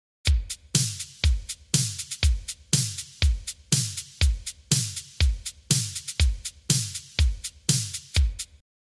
всем здравствуйте! не могли бы вы мне помочь? как добиться звучания снэйра и бочки как у c.c.catch?